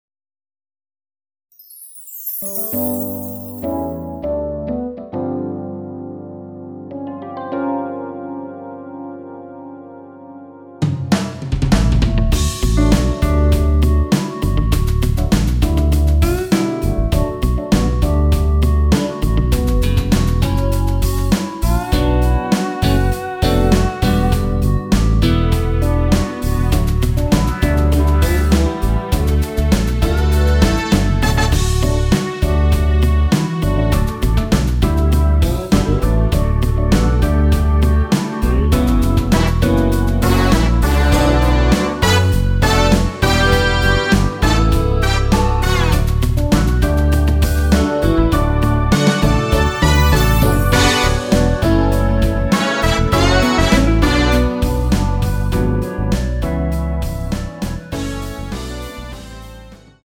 원키에서(-3)내린 멜로디 포함된 MR입니다.(미리듣기 확인)
멜로디 MR이라고 합니다.
앞부분30초, 뒷부분30초씩 편집해서 올려 드리고 있습니다.
중간에 음이 끈어지고 다시 나오는 이유는